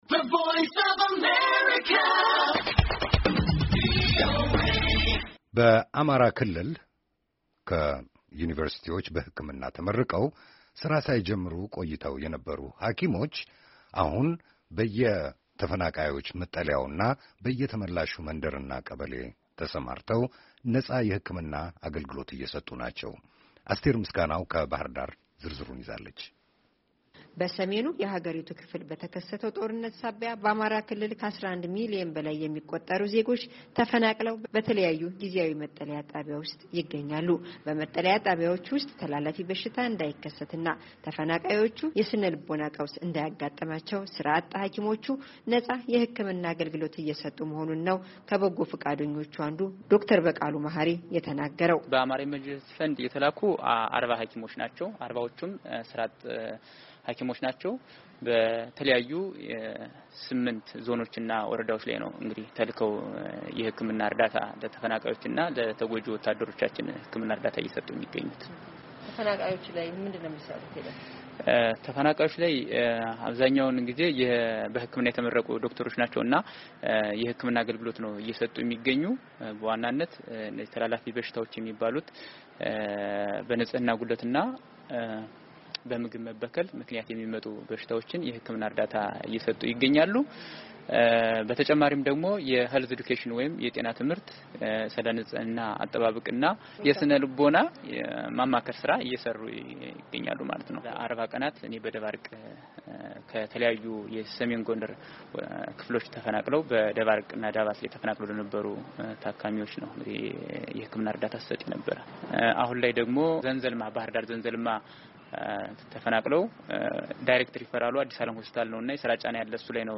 ነፃ የህክምና አገልግሎት ከሚሰጡ ወጣቶች ጋር ቆይታ አድርጋለች።